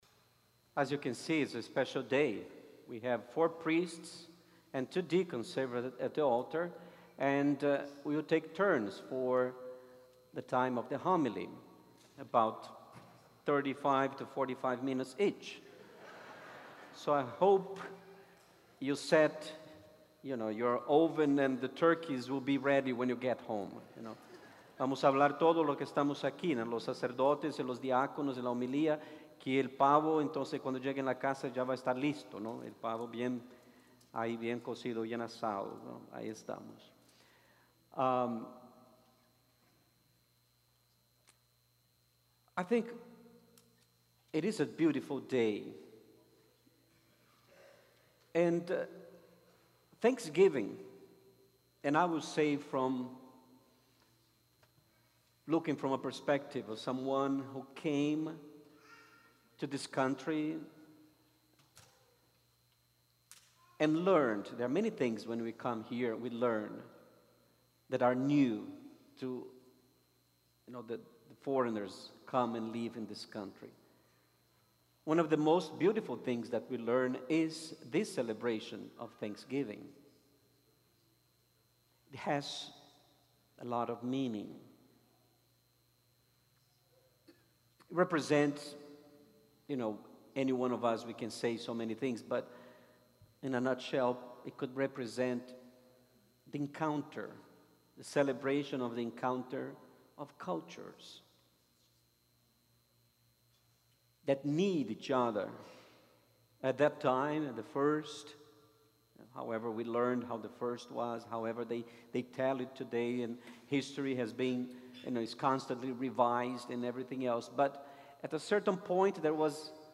Thanksgiving Tri-lingual Mass